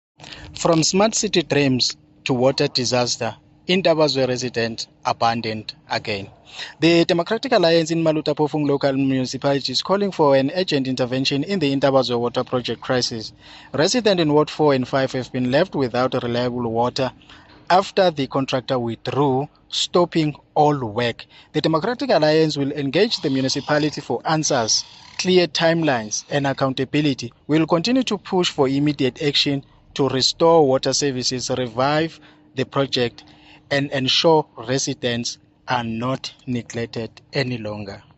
English soundbite by Cllr Richard Khumalo,